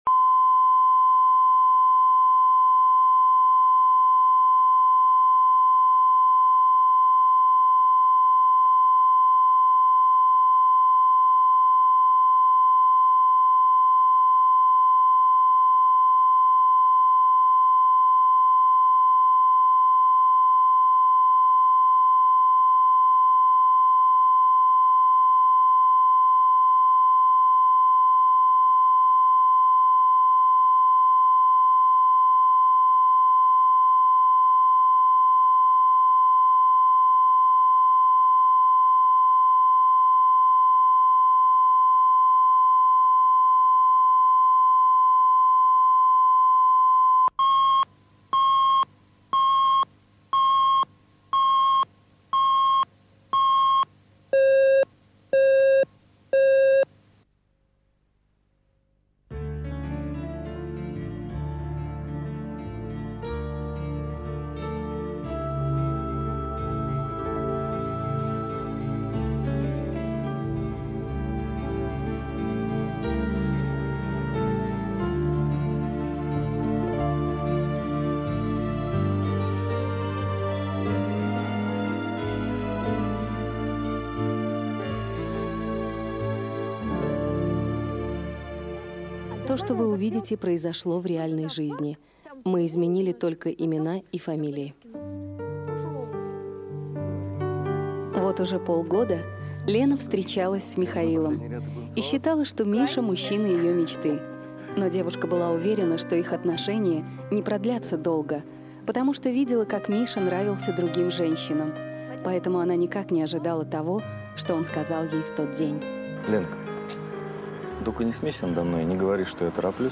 Аудиокнига Самая красивая